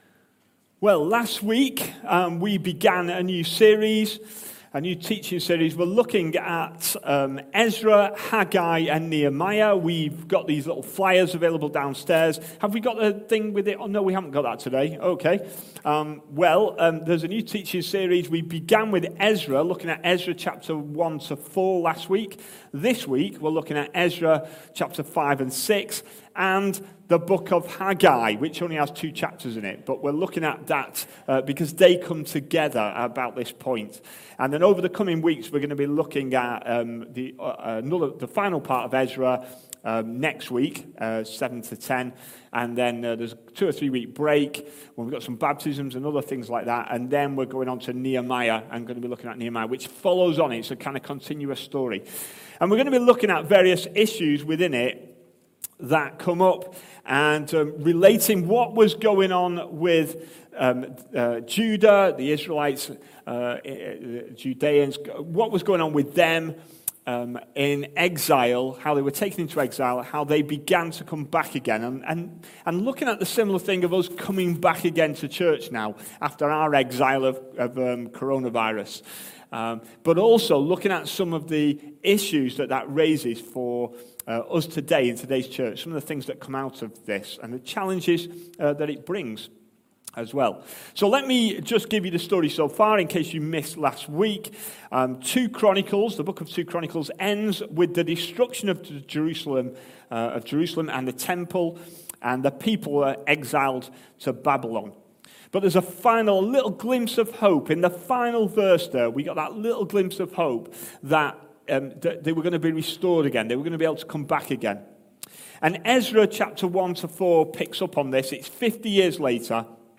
Reprioritising our lives A sermon preached on 12th September, 2021, as part of our EZRA, HAGGAI, and NEHEMIAH - God’s restoration plan… series. EZRA 5-6 & HAGGAI